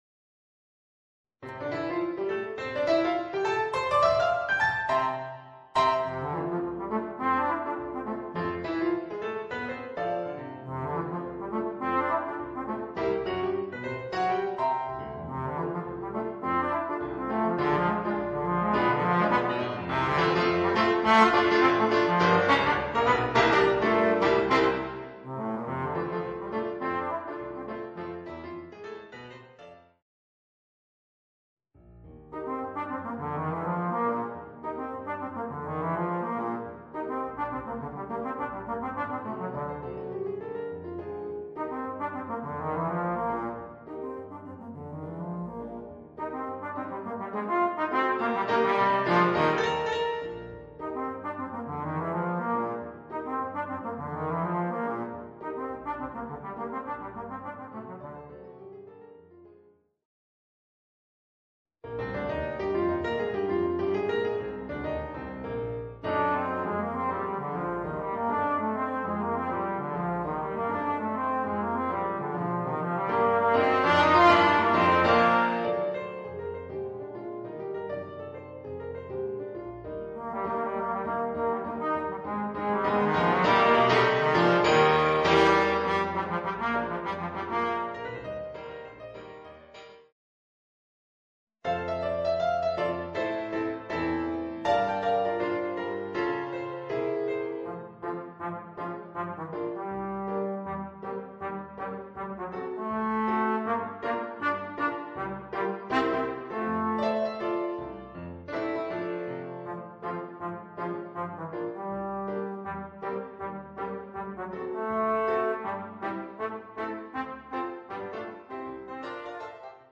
Voicing: Trombone and Piano